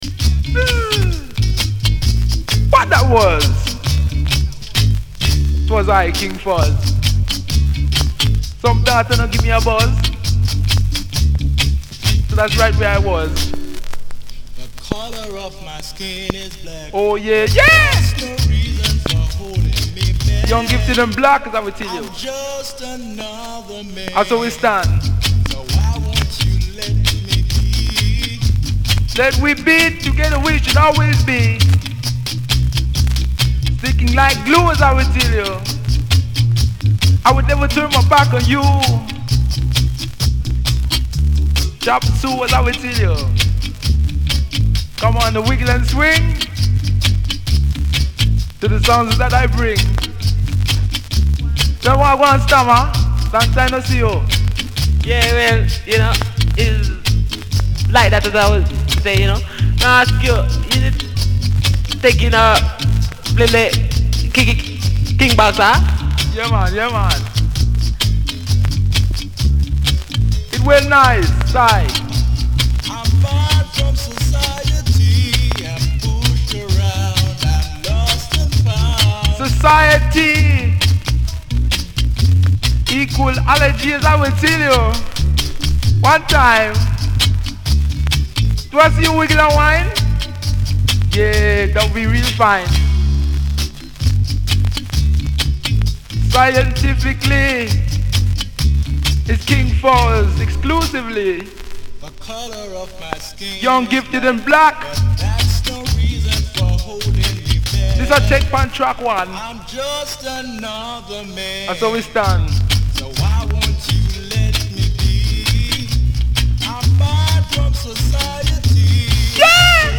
in an exited and joyous manner